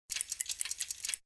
CHQ_FACT_switch_depressed.ogg